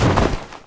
Clothes.wav